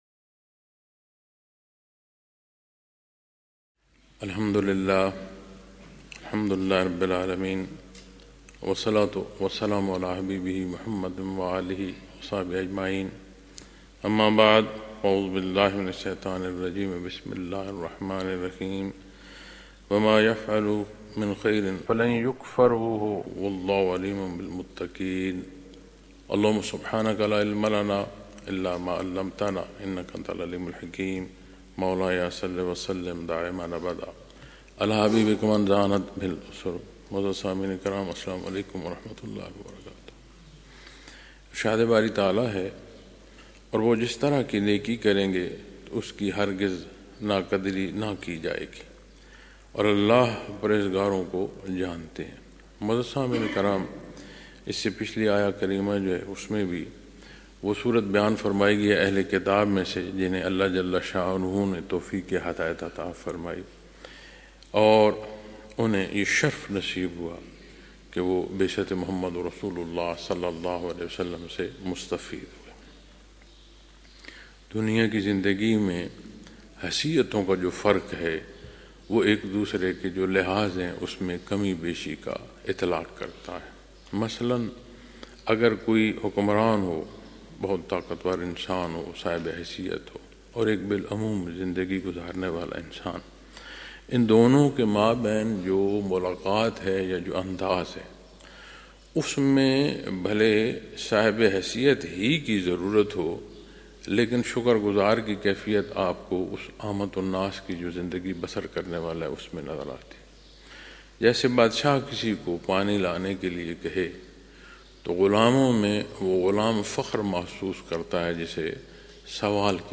Lectures in Munara, Chakwal, Pakistan on March 6,2026